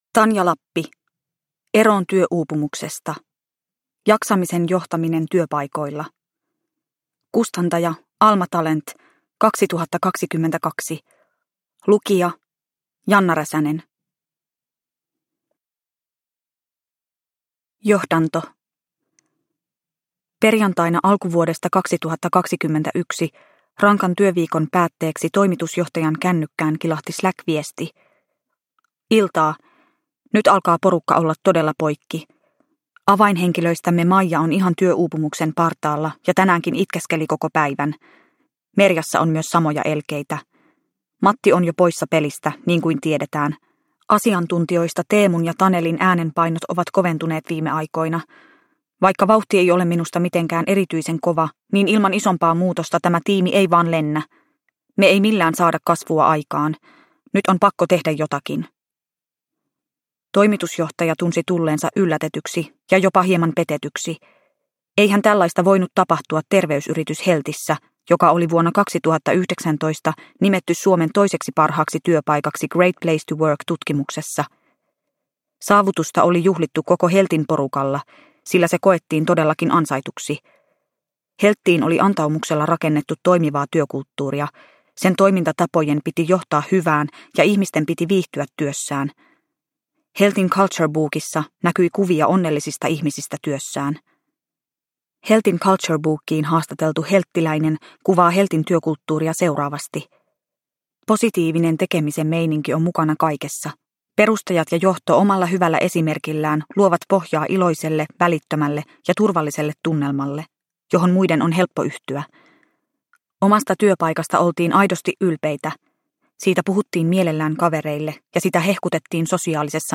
Eroon työuupumuksesta – Ljudbok – Laddas ner